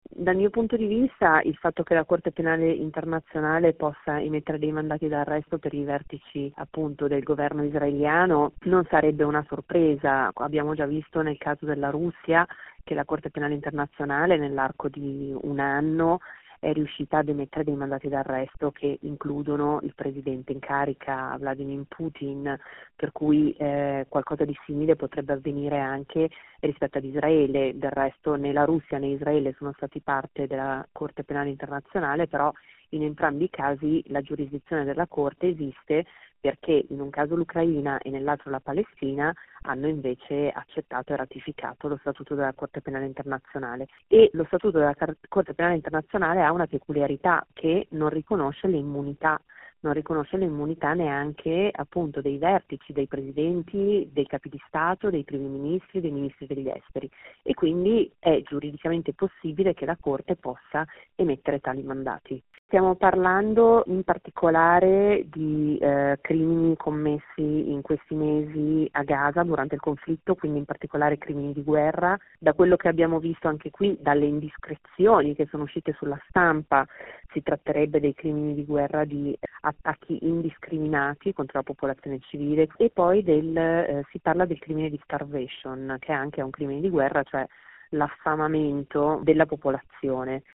Il racconto della giornata di lunedì 29 aprile 2024 con le notizie principali del giornale radio delle 19.30. L’attesa per la risposta di Hamas alla proposta di tregua presentata da Israele.